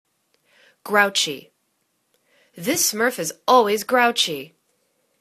grou.chy     /'growchi/    adj